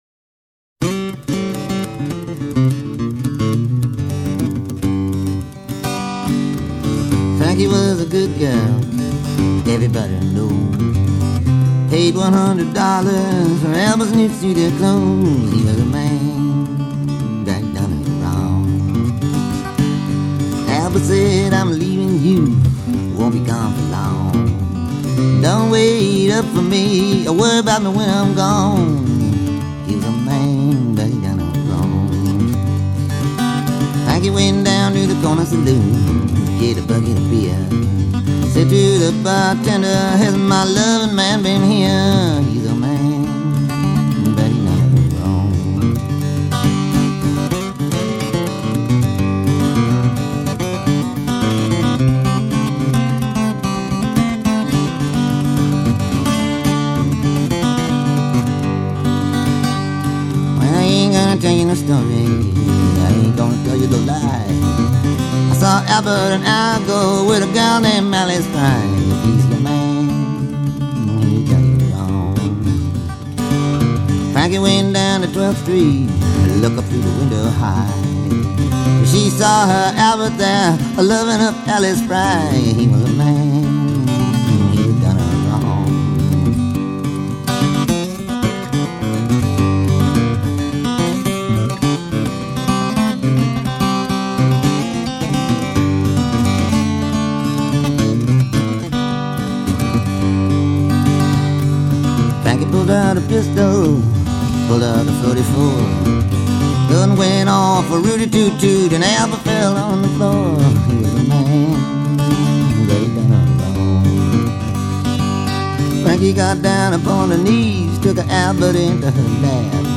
Folk, Acoustic